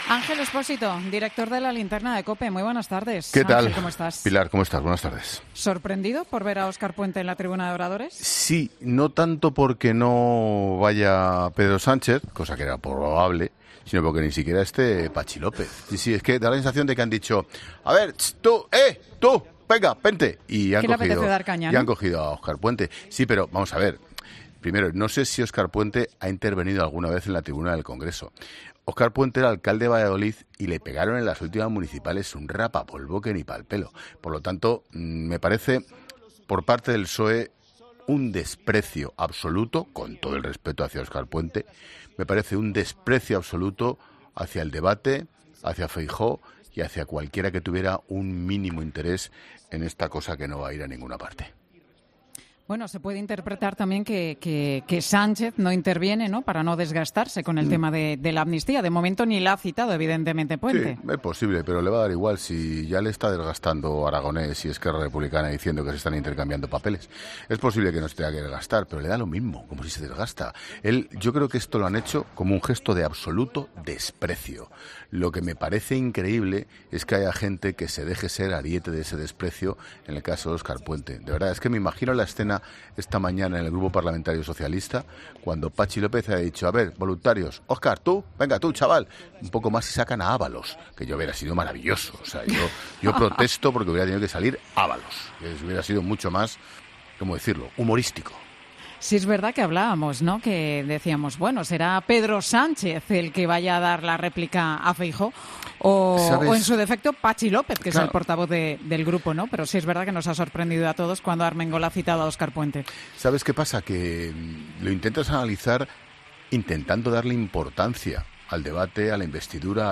El director de 'La Linterna', Ángel Expósito, analizó en 'Mediodía COPE' la aparición de Óscar Puente en la tribuna del Congreso.